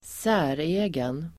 Uttal: [²s'ä:re:gen]